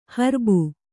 ♪ harbu